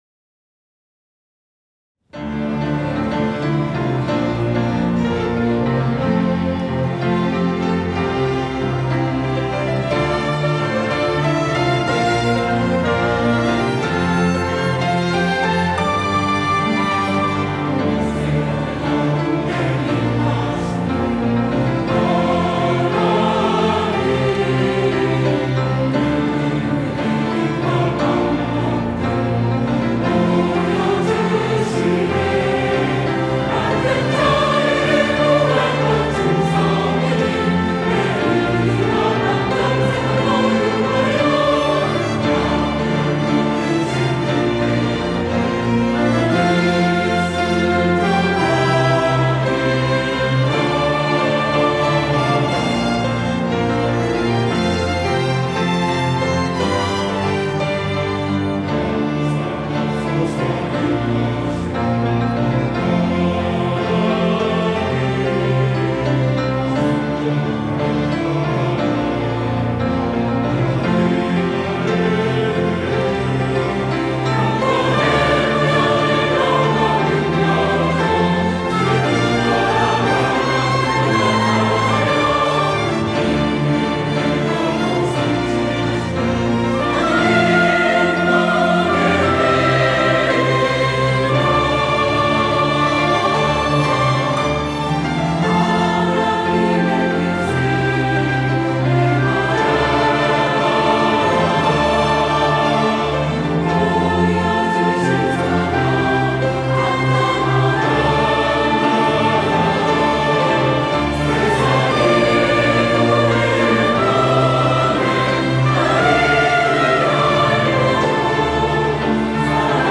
호산나(주일3부) - 하나님의 뜻을 행하라
찬양대